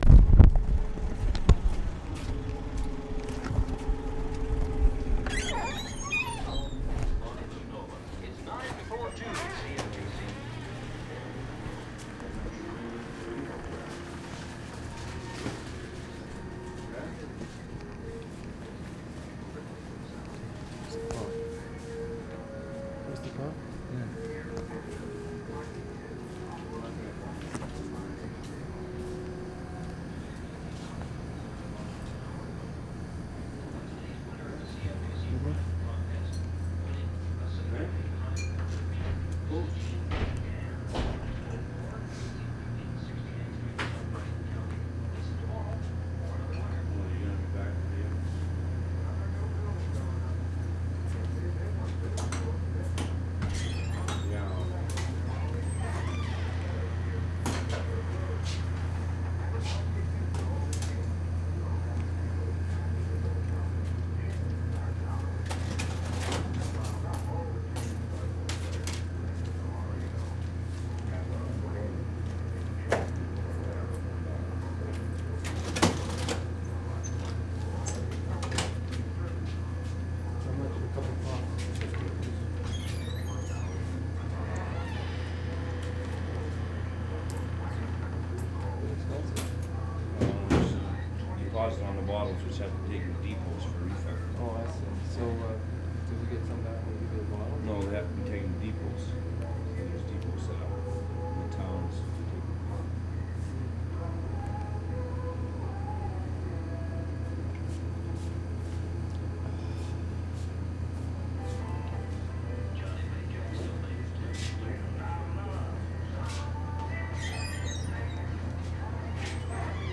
MORLEY, ALBERTA Nov. 13, 1973
IN CAFE/STORE 2'15"
Walking inside. Heavy hum from freezer, radio in background, cash register. People's voices submerged in this lo-fi ambience. Squeaky door opened and closed throughout.